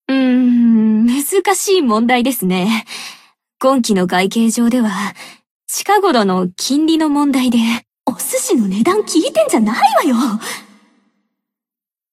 贡献 ） 分类:蔚蓝档案 分类:蔚蓝档案语音 协议:Copyright 您不可以覆盖此文件。